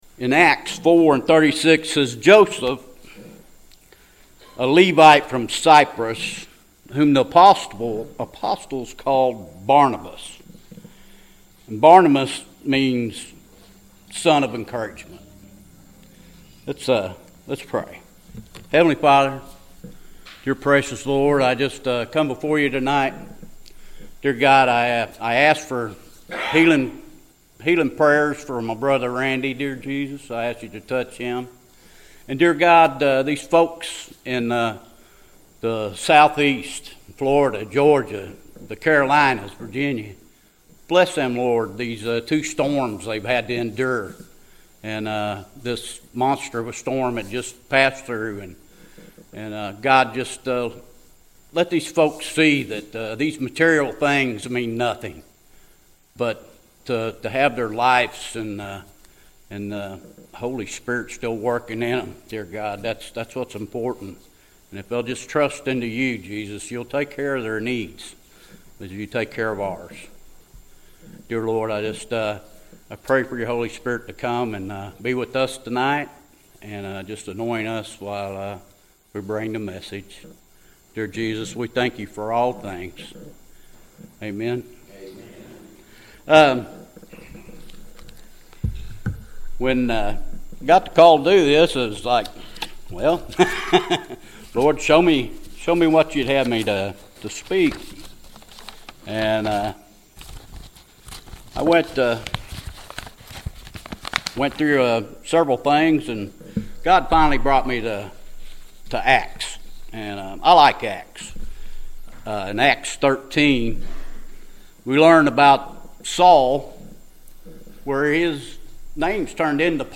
Barnabas, Son Of Encouragement-PM Service